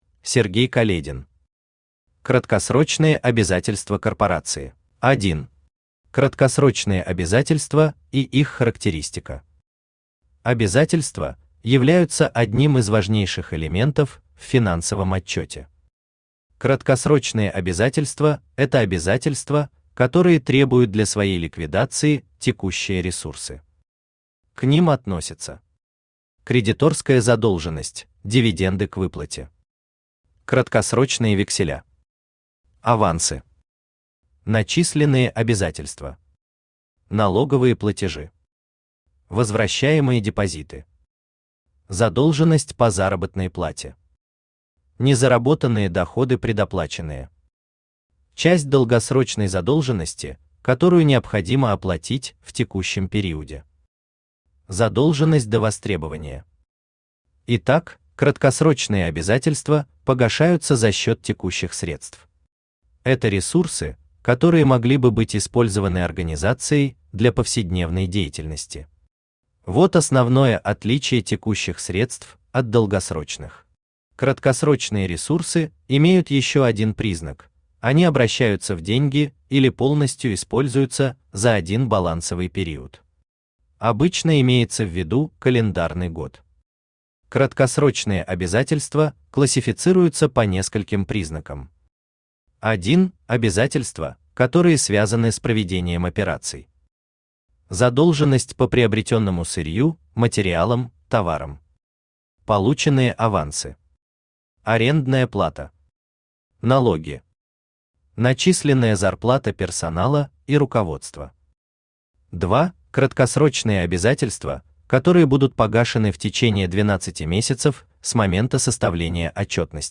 Аудиокнига Краткосрочные обязательства корпорации | Библиотека аудиокниг
Aудиокнига Краткосрочные обязательства корпорации Автор Сергей Каледин Читает аудиокнигу Авточтец ЛитРес.